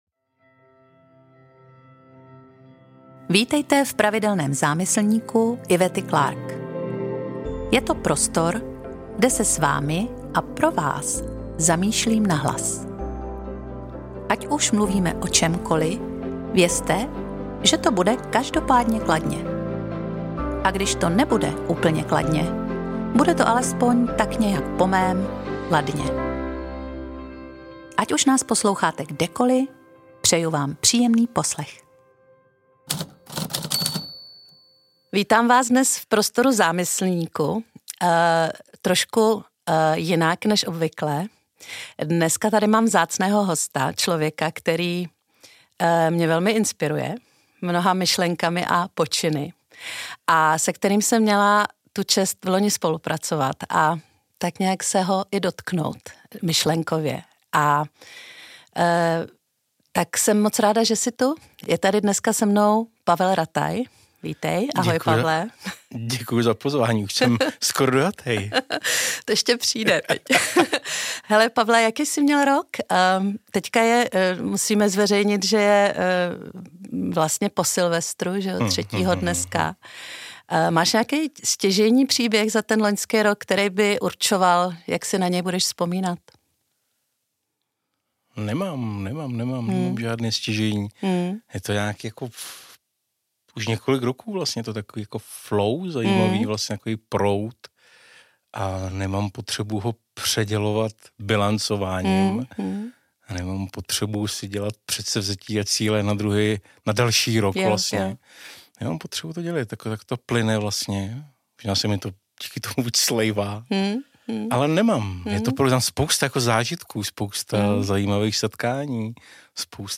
Náš rozhovor je naživo, a zároveň živý v tom nejhlubším slova smyslu.